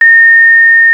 RetroGamesSoundFX / Ringing
Ringing11.wav